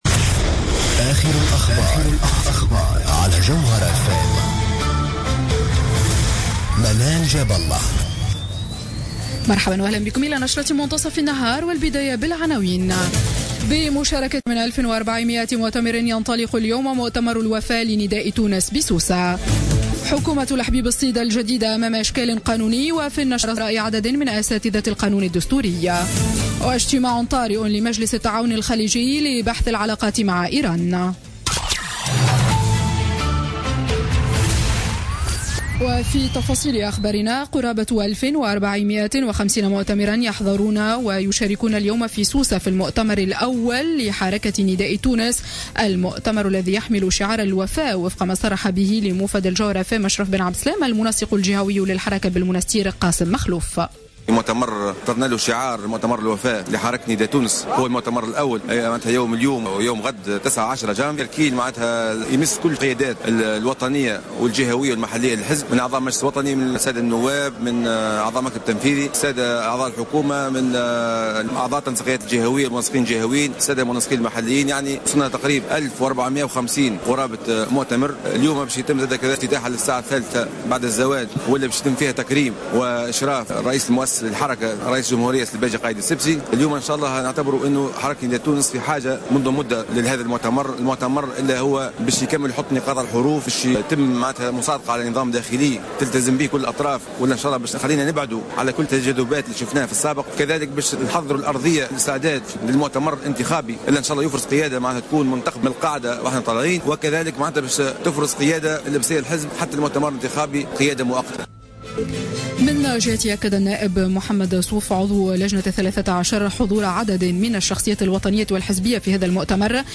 نشرة أخبار منتصف النهار ليوم السبت 09 جانفي 2016